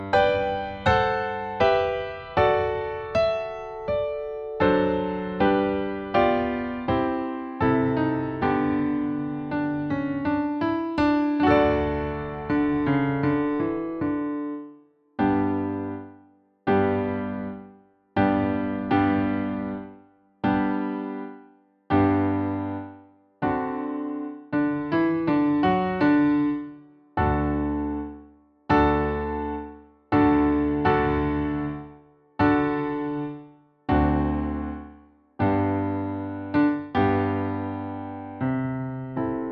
nagranie akompaniamentu pianina
I część: 80 bmp – wersja ćwiczeniowa
Nagranie nie zawiera rubat ani zwolnień.
Nagranie dokonane na pianinie cyfrowym, strój 440Hz
piano